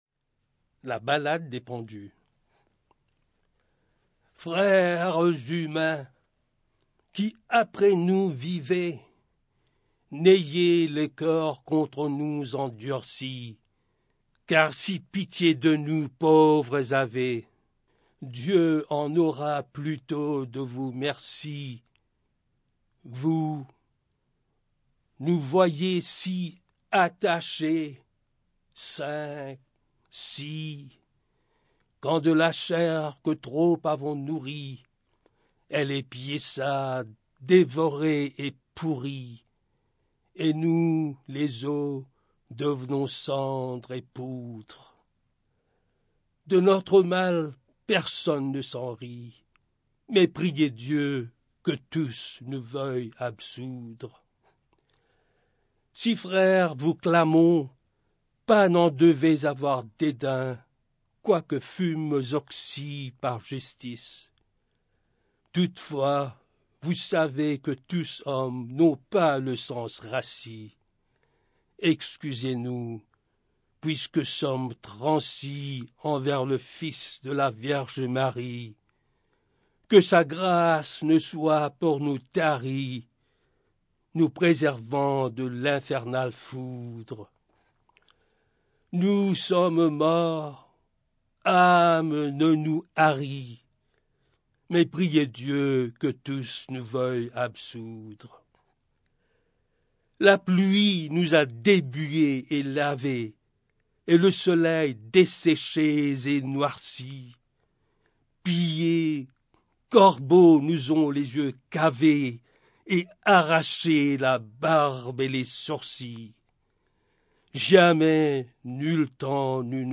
Audio non-musical
poetry